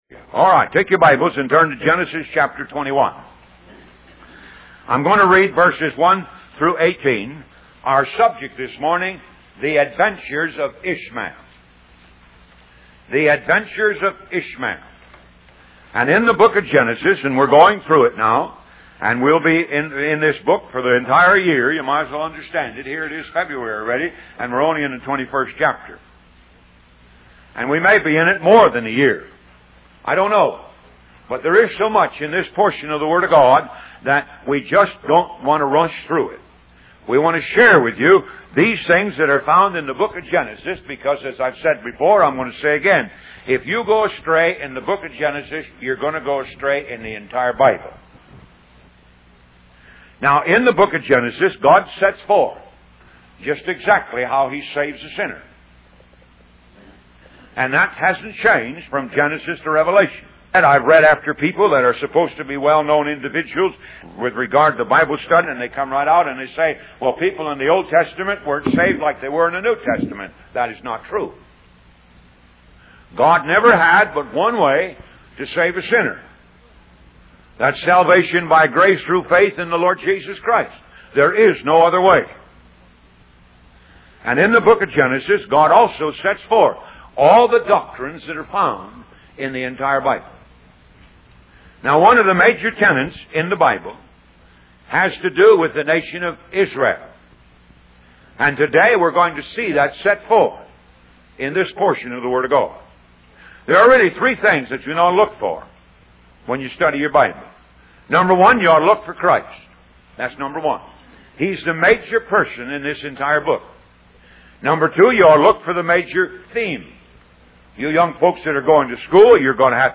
Talk Show Episode, Audio Podcast, Moga - Mercies Of God Association and The Adventures Of Ishmael on , show guests , about The Adventures Of Ishmael, categorized as Health & Lifestyle,History,Love & Relationships,Philosophy,Psychology,Christianity,Inspirational,Motivational,Society and Culture